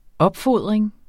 Udtale [ ˈʌbˌfoðˀʁeŋ ]